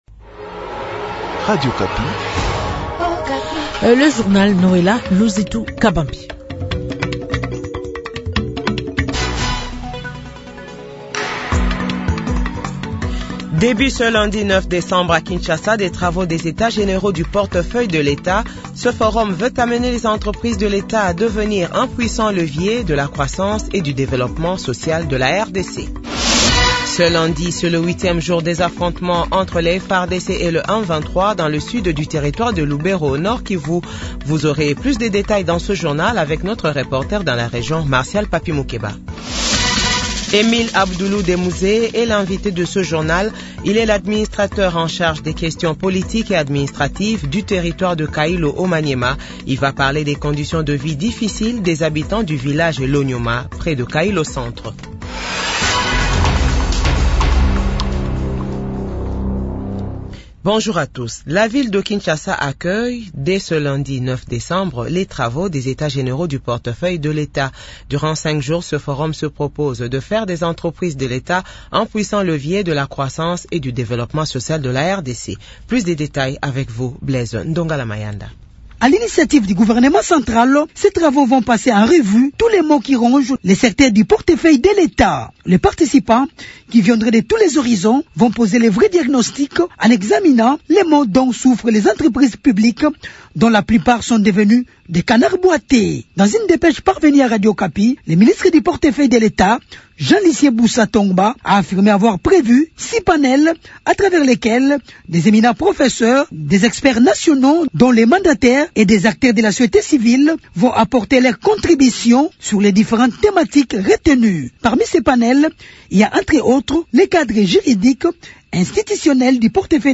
JOURNAL FRANÇAIS DE 15H00